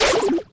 rt_dry_fire_01.ogg